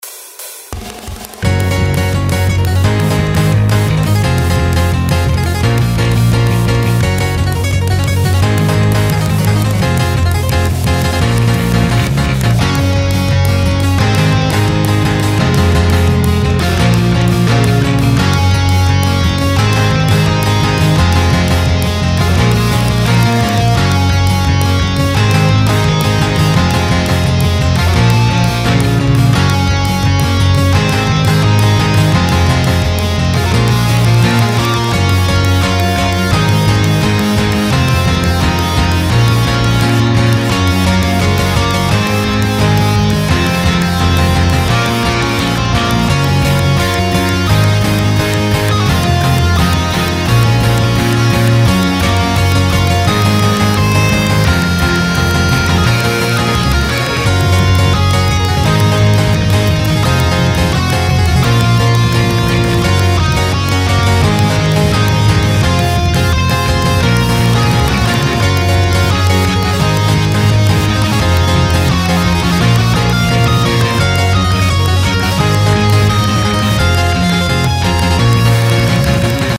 それぞれ１ループの音源です♪
イントロあり